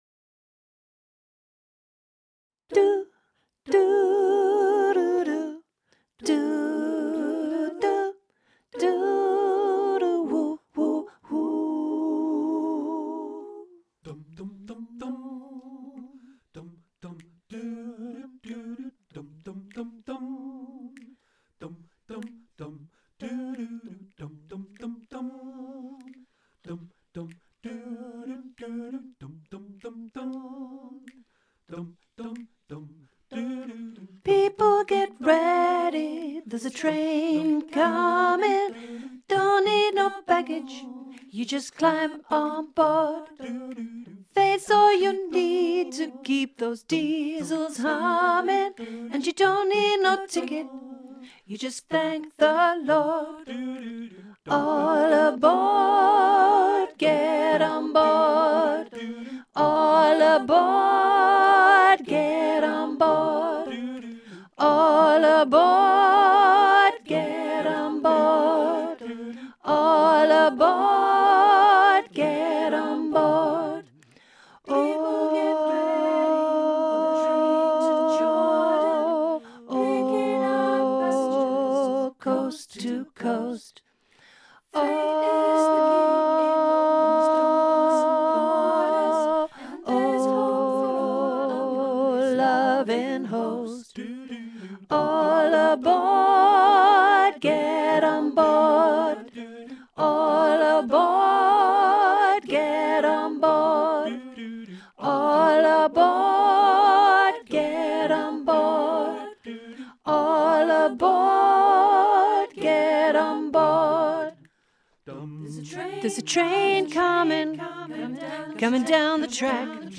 63 People get ready (Alto 1 learning track)
Genre: Choral.